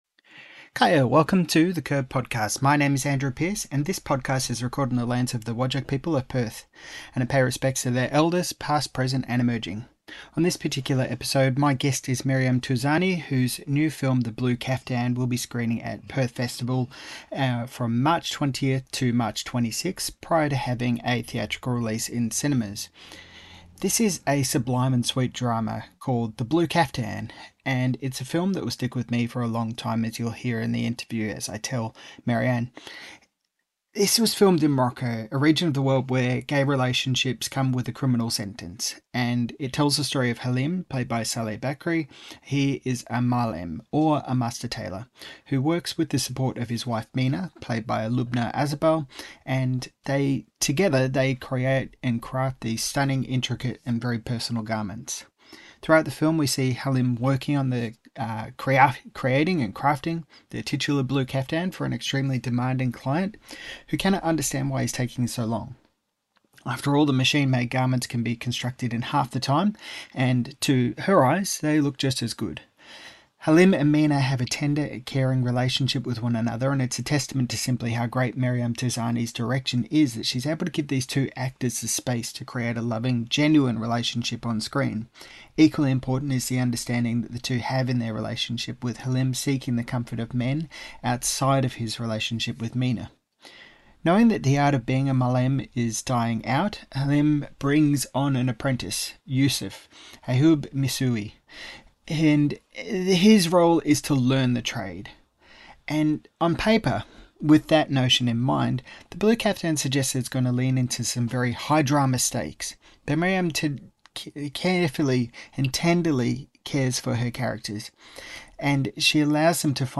The Blue Caftan Director Maryam Touzani Talks Emotional Intimacy and Sharing Personal Stories with the World in This Interview - The Curb